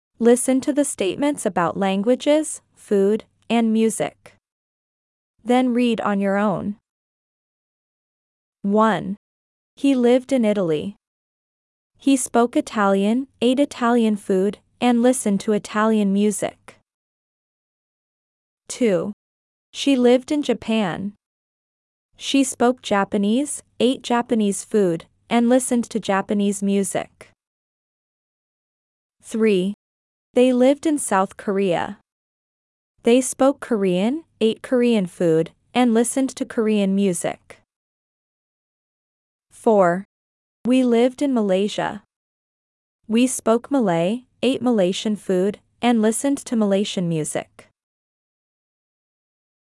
As you progress through the exercises and listen to the example statements and dialogs, you’ll not only strengthen your grasp on key English tenses and sentence structures but also enrich your vocabulary with the English names of countries from around the globe.